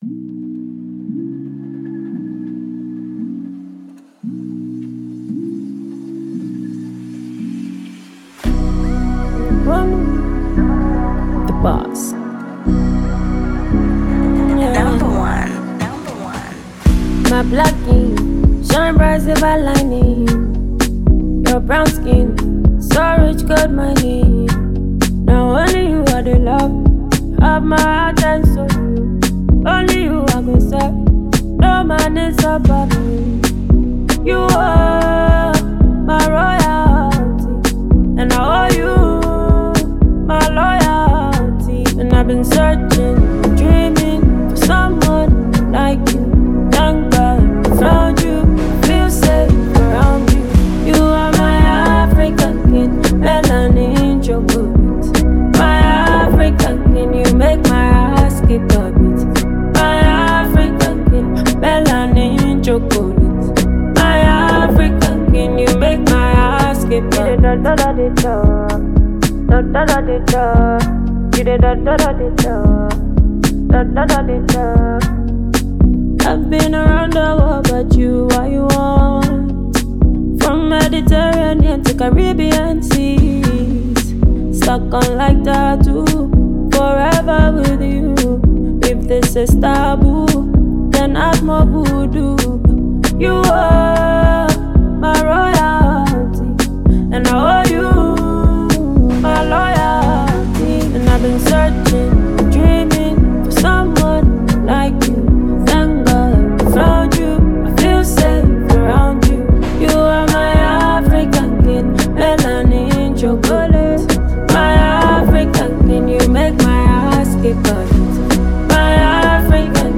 Ghanaian female artiste